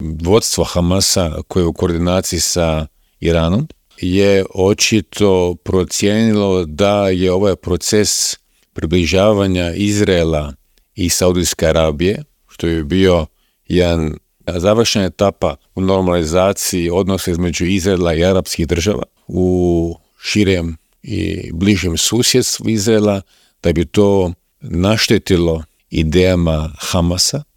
Aktualnu situaciju na Bliskom istoku, ali i u Europi, u Intervjuu Media servisa analizao je bivši ministar vanjskih i europskih poslova Miro Kovač.